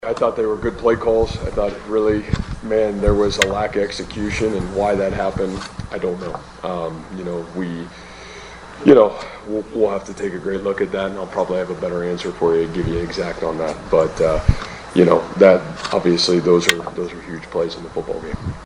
That’s ISU coach Matt Campbell.